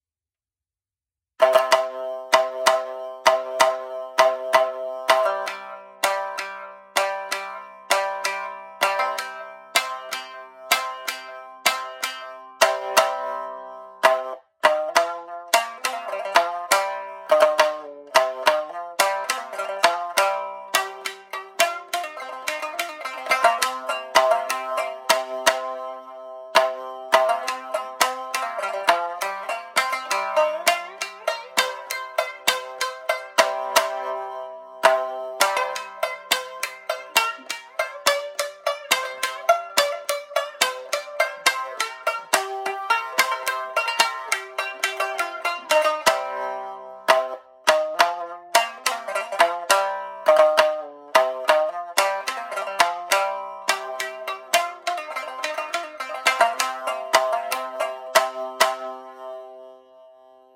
今回は自分の演奏を録音し、ブログの中で聴けるようにしてみたので、時間がありましたら三味線の音も聴きながらブログを読んでいただけると嬉しいです。
ちゃんとした録音機材などを持っているわけではないので、音質はあまりよくないかもしれませんが、ご了承ください。
中節は、三味線よりも唄を引き立たせるため、ゆったりとしたテンポで、3拍子のリズムが特徴的な曲です。